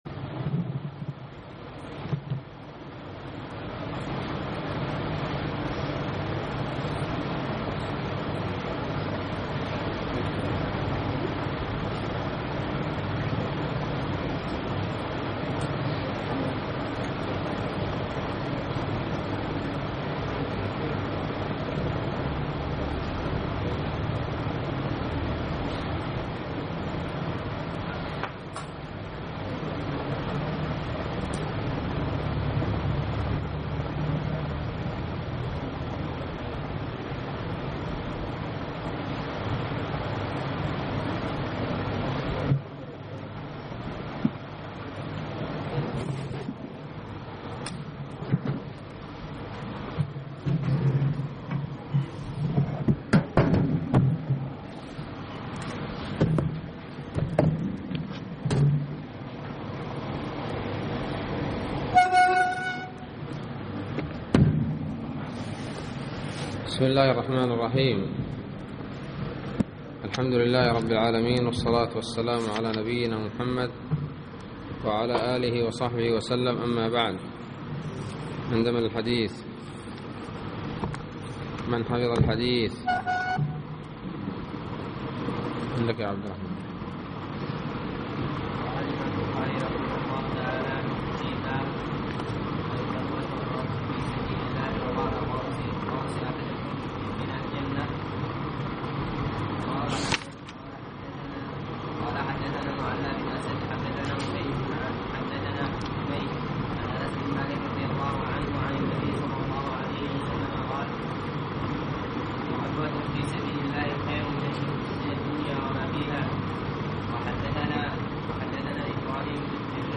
الدرس السابع : بَابُ الحُورِ العِينِ، وَصِفَتِهِنَّ يُحَارُ فِيهَا الطَّرْفُ، شَدِيدَةُ سَوَادِ العَيْنِ، شَدِيدَةُ بَيَاضِ العَيْنِ